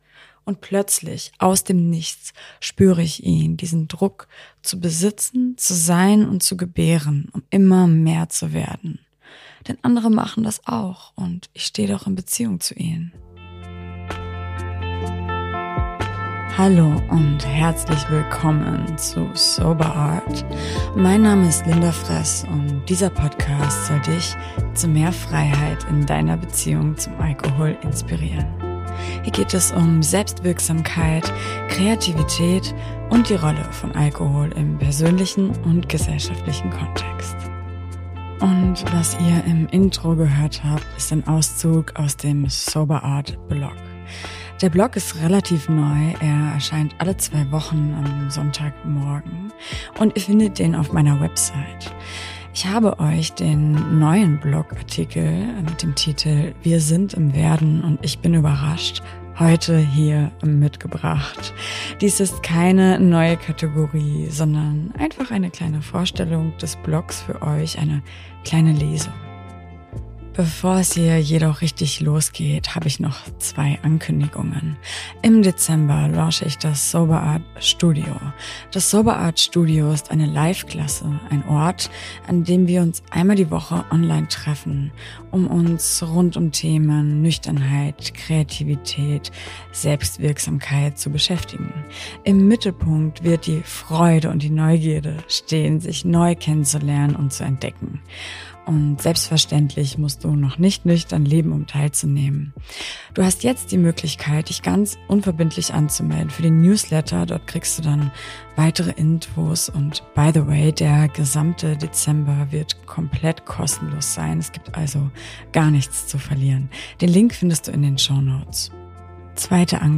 Ihr Lieben! Heute dürft ihr eine kleine Lesung genießen, denn ich